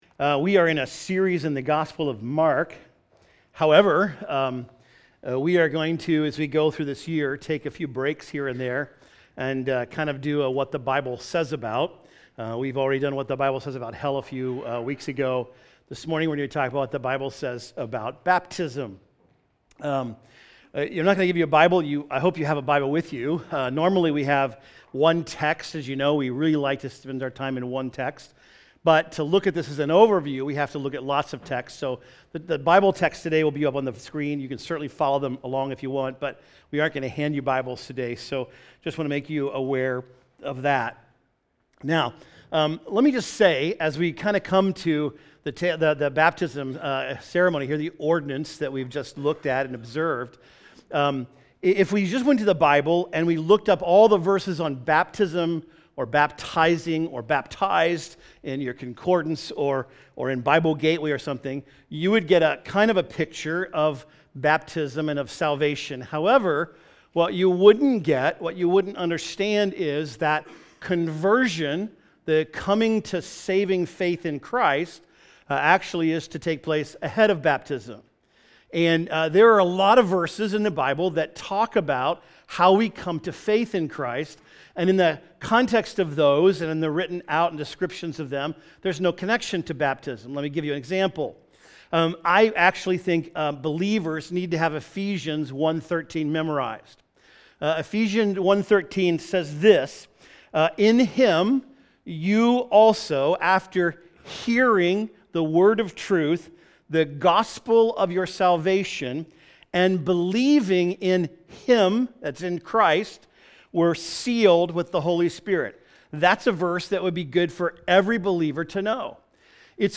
This week we are taking a look at what the Bible says about baptism. We are celebrating 9 baptisms this morning and then talking about the process of coming to faith and then proclaiming it in baptism. We will look at why be baptized, what does baptism do, and who should be baptized.